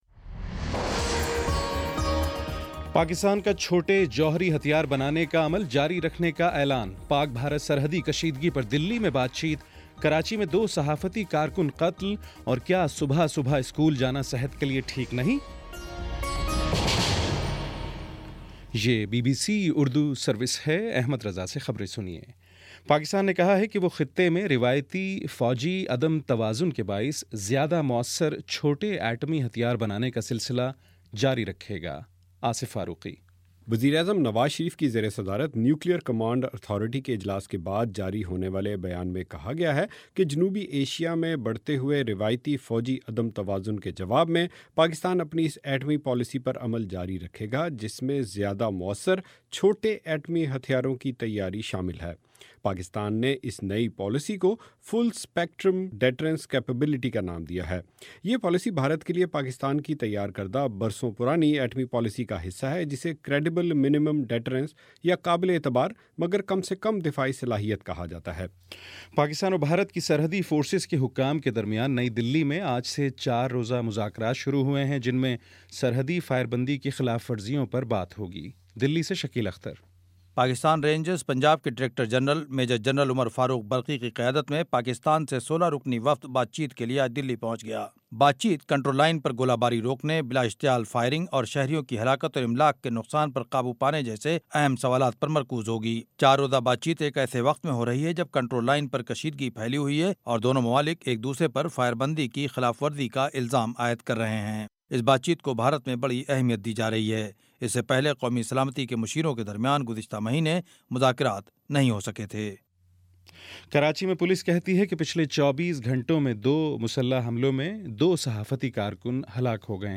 ستمبر9 : شام پانچ بجے کا نیوز بُلیٹن